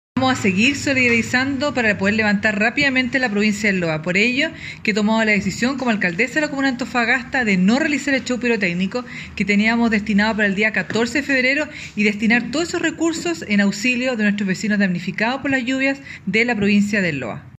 Cuña-Alcaldesa-Karen-Rojo.mp3